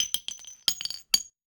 weapon_ammo_drop_20.wav